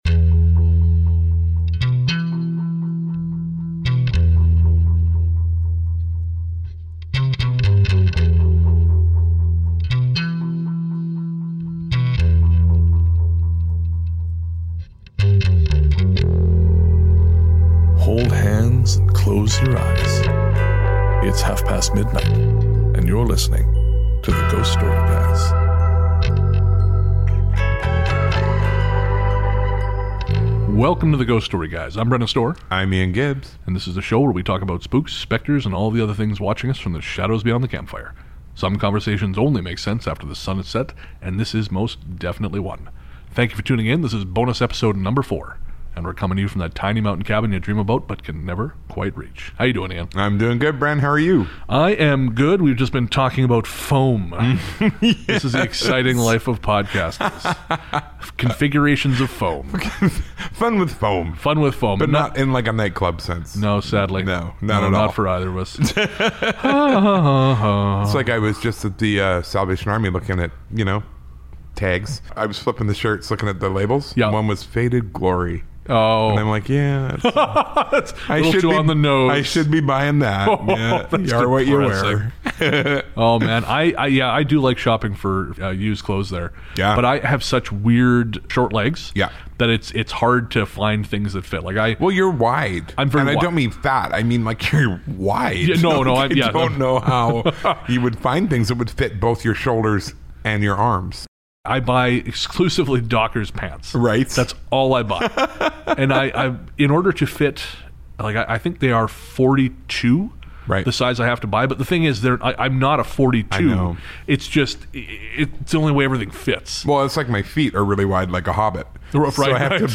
For our fourth bonus episode we watched Jeremy Corbell's new documentary "Hunt For the Skinwalker", then discussed our thoughts on the film and the phenomenon which inspired it. It's a bonus episode so prepare for wild theories and a surprisingly long conversation about fast food.